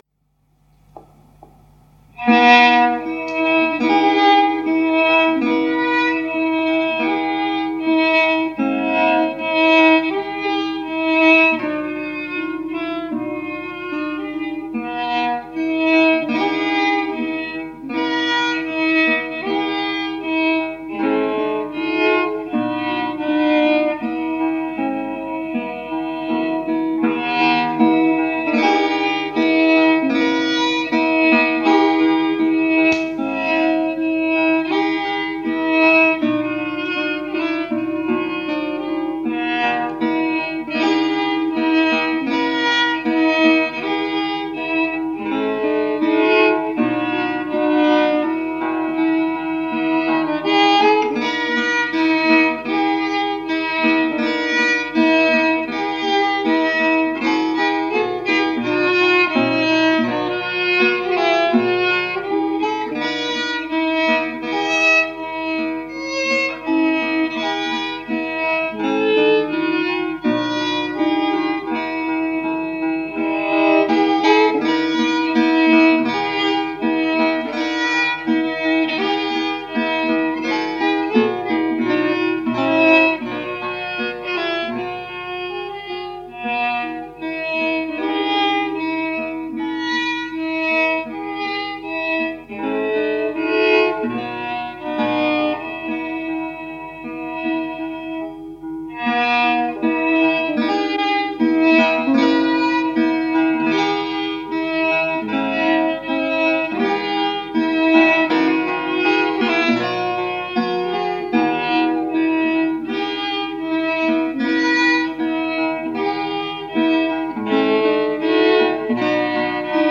playing fiddle